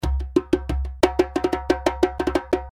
Djembe loops - 90 bpm (21 variatioans)
Real djembe loops playing at 90 bpm.
The Djembe loops are already in mix mode, with light compression and EQ.
The Djembe was recorded using vintage neumann u87 as main microphone, And 2 451 AKG microphones for the stereo ambient sound. The loops are dry with no effect , giving you freedom, adding the right effect to your project. Djembe is west african drum but the loops here are more ethnic, arabic and brazilian style .